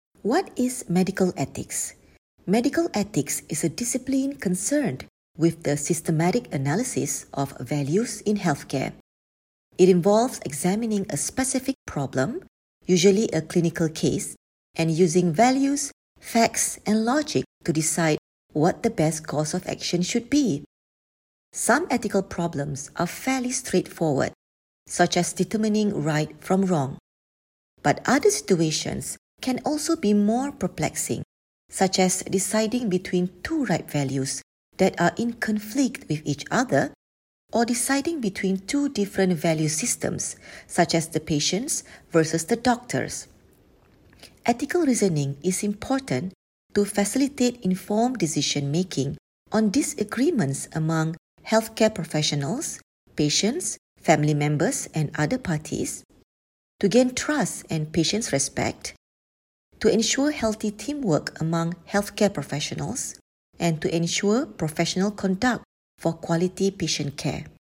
Narration audio (MP3) Contents Home What is Medical Ethics?